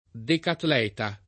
decatleta [ dekatl $ ta ]